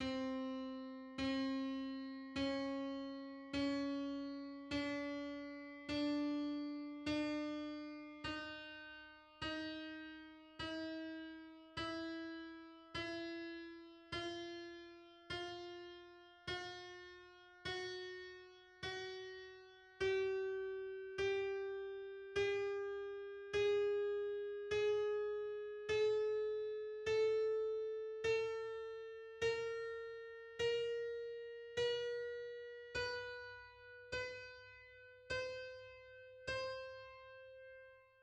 Play Each step represents a frequency ratio of 312 , or 38.71 cents (Play).
31-tet_scale_on_C.mid.mp3